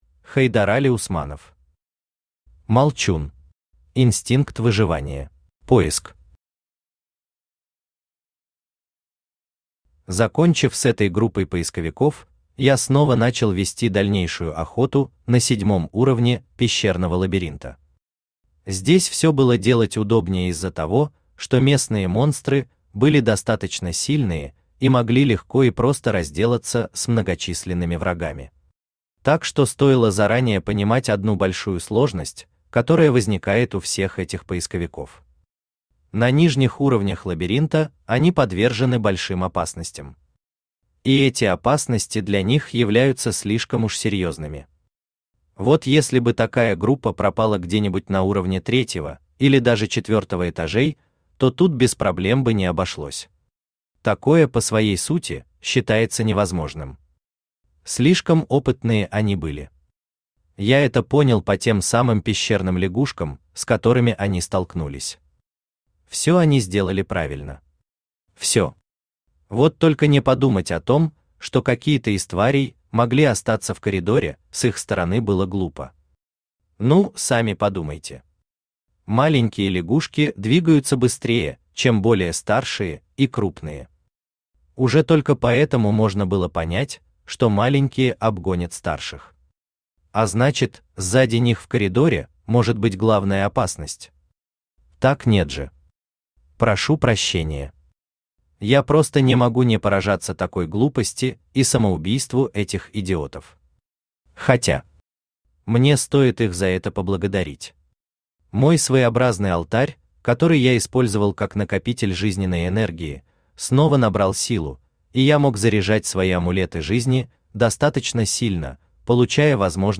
Инстинкт выживания Автор Хайдарали Усманов Читает аудиокнигу Авточтец ЛитРес.